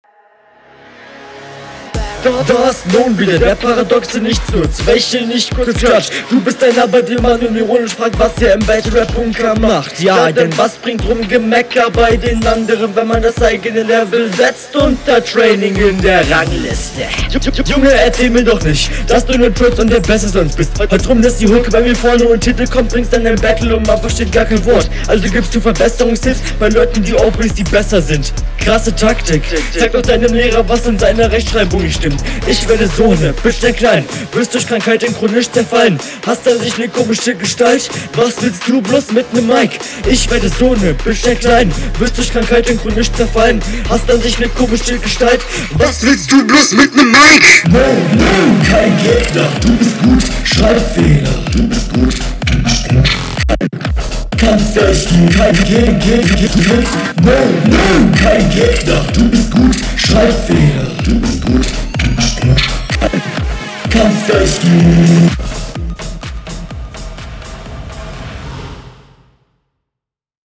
Doubles sind leider extrem dissonant und wieder rappst du übersteuert in das Mikrofon.
Mische leider etwas verkackt.